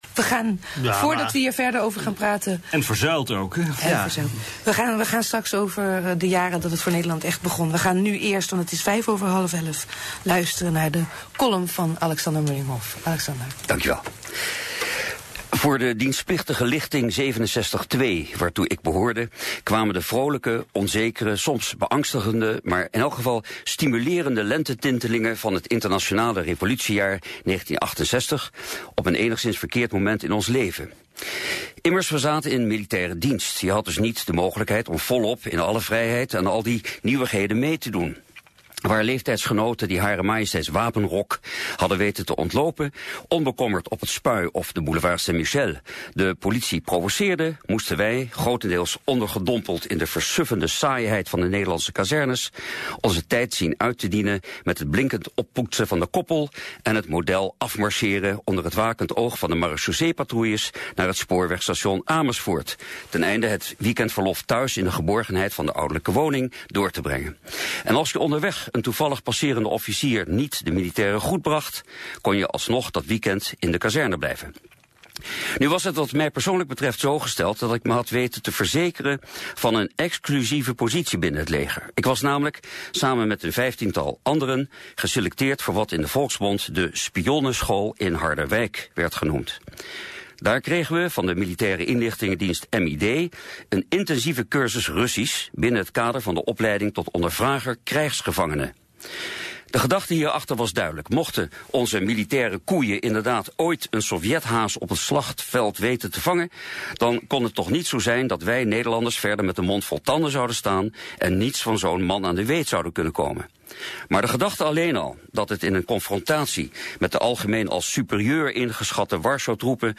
Column